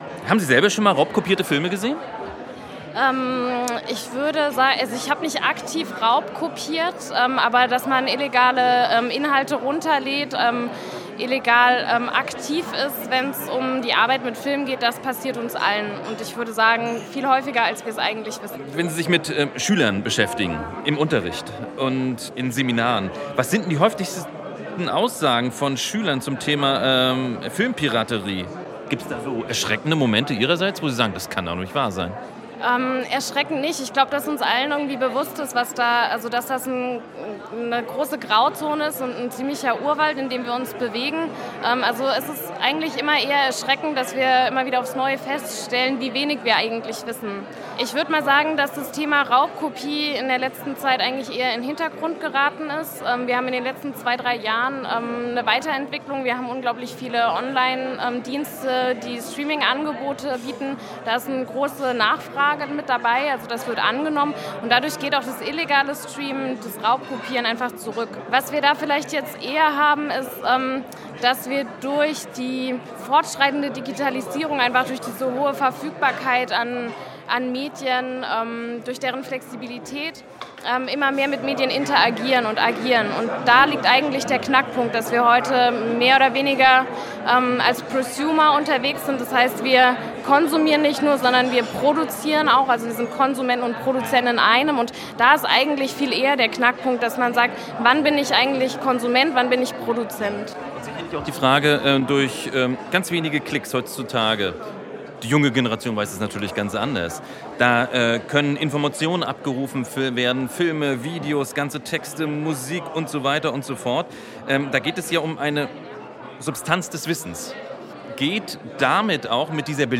Ein Gespräch über geistiges Eigentum im Zeitalter der technischen Reproduzierbarkeit.